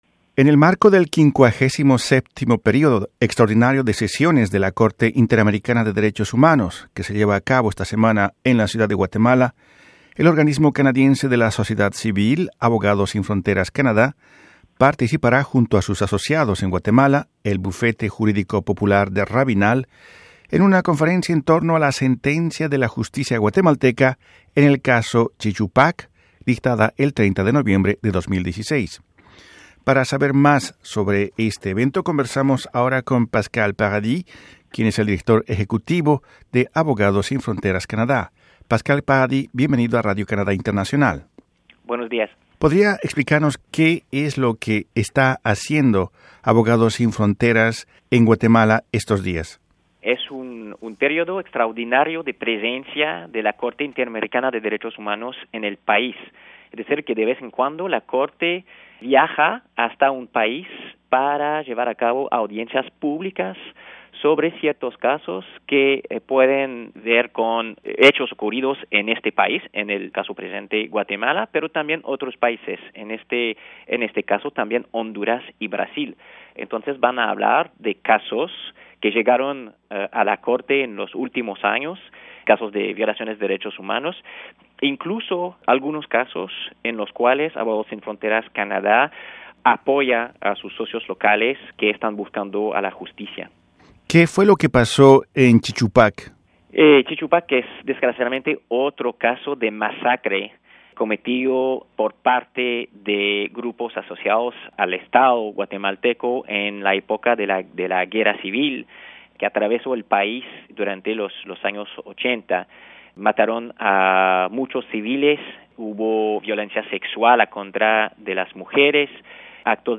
Radio Canadá internacional pudo conversar sobre el caso con